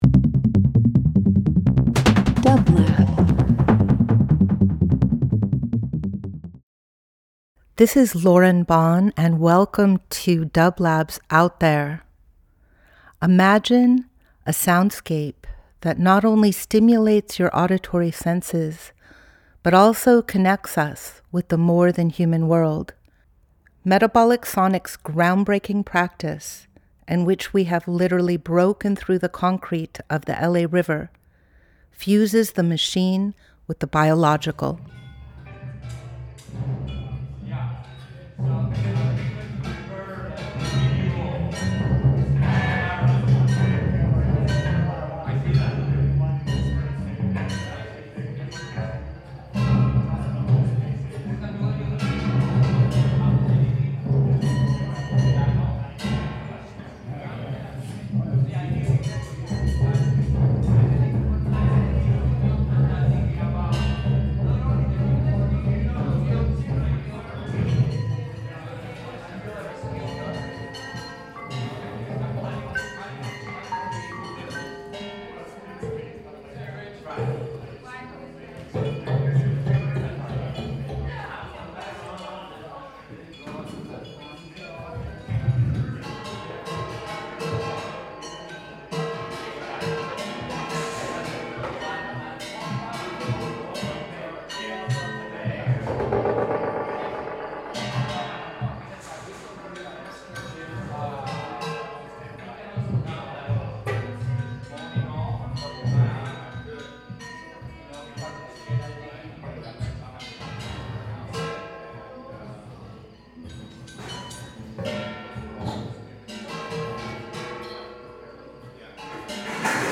Each week we present field recordings that will transport you through the power of sound.
Music of the kitchen! We are expanding our practice with cheLA, a group of artist/activists in Buenos Aires, blending their tradition of making ñoqui on the 29th of each month with Metabolic’s Rituals of Dining. Our food prep tables were MIC-ed and we included pots, pans, traditional string instruments, gongs, baschets, and voice – connecting to Buenos Aires through zoom.